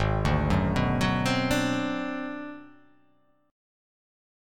G#7#9b5 Chord